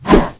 WAV · 17 KB · 單聲道 (1ch)